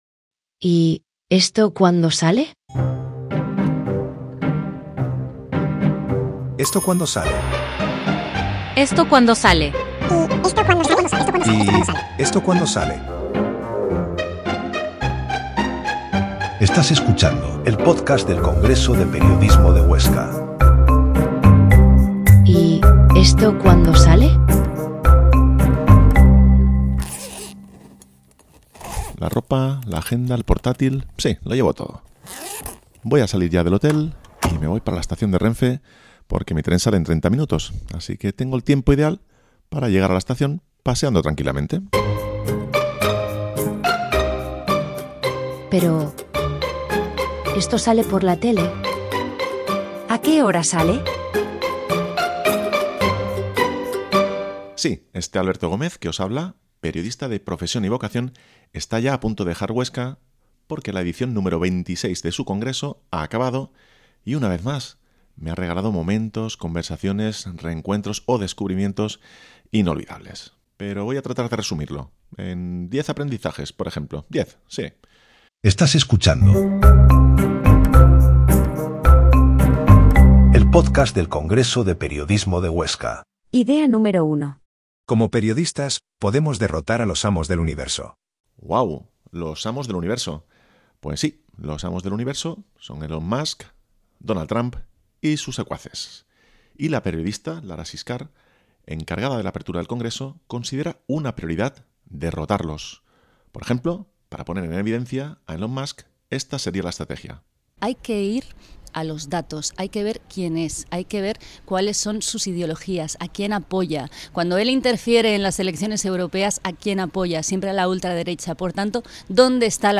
¡Hasta un tango!